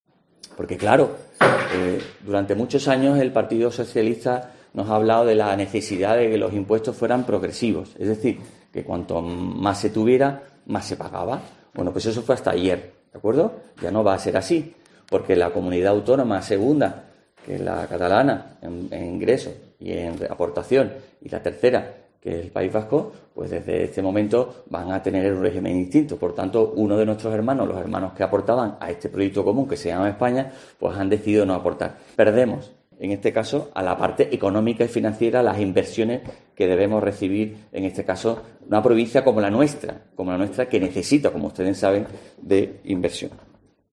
Bruno García, presidente provincial del PP, habla de los perjuicios de los acuerdos de Sánchez